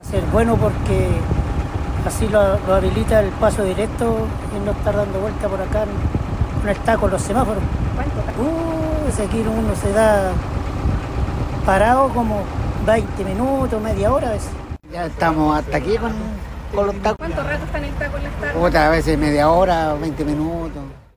Noticia que también fue bien recibida por conductores de la locomoción colectiva, que reportan hasta media hora de espera a causa del taco que se genera en el sector en horarios punta.
micreros-paso-bajo-nivel.mp3